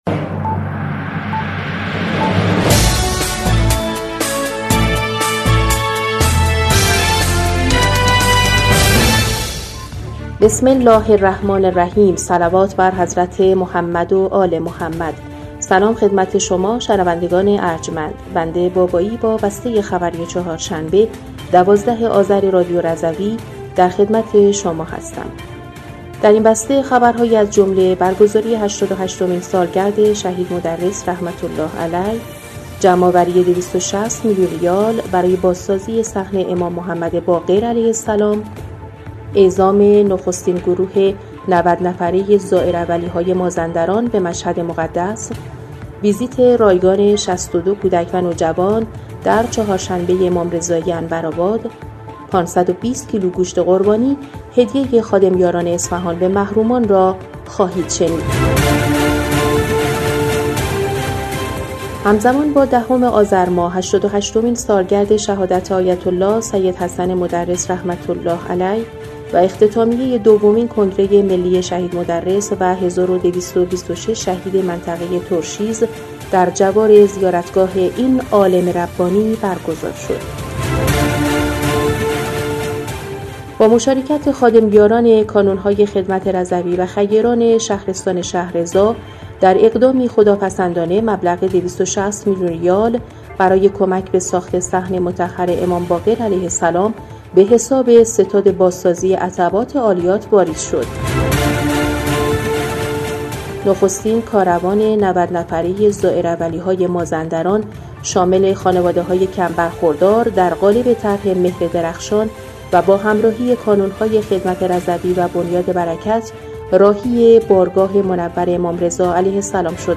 بسته خبری ۱۲ آذر ۱۴۰۴ رادیو رضوی؛